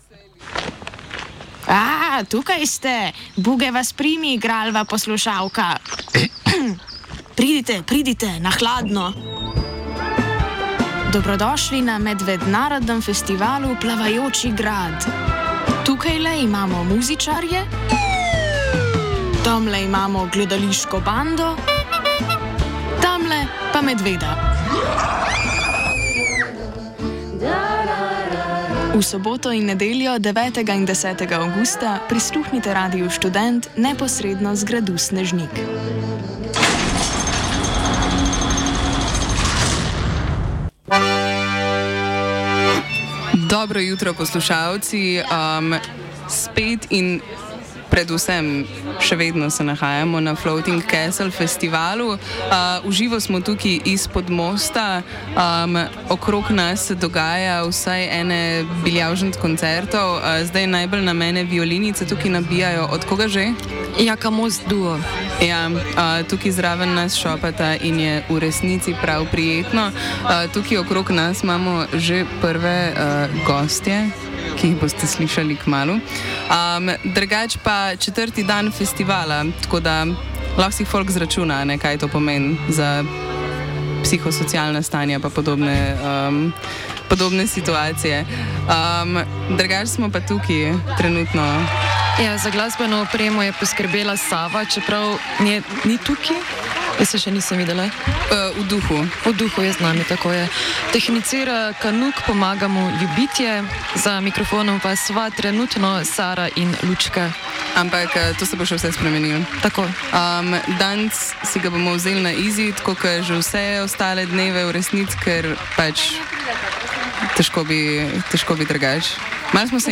Javljanja sodelavcev Radia Študent s festivalov in preostalih muzičnih manifestacij.